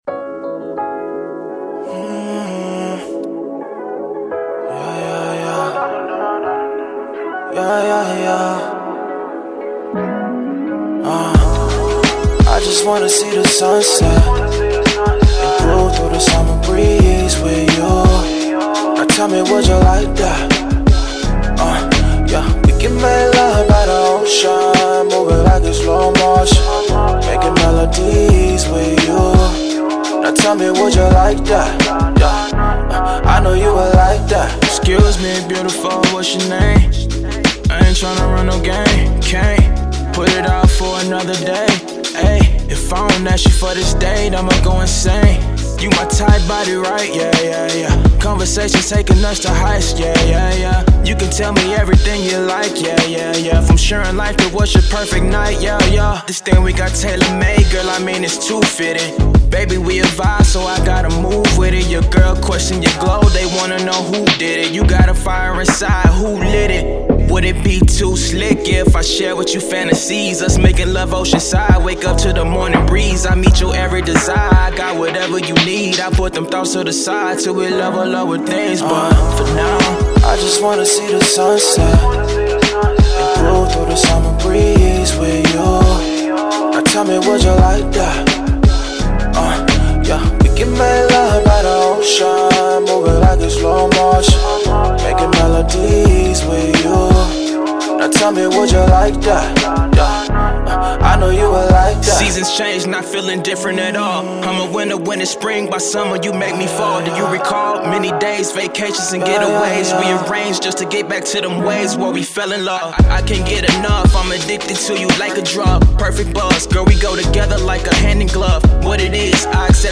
R&B
C# Minor
Chill Love Song